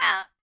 Sfx Player Boulder Pickup Sound Effect
Download a high-quality sfx player boulder pickup sound effect.
sfx-player-boulder-pickup.mp3